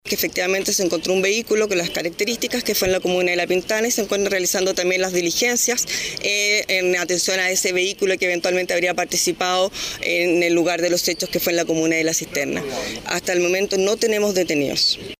De acuerdo a la fiscal jefe de la Fiscalía de Flagrancia de la Fiscalía Metropolitana Sur, Nadia Mondiglio, confirmó que en La Pintana se encontró un automóvil abandonado que habría sido utilizado por los delincuentes.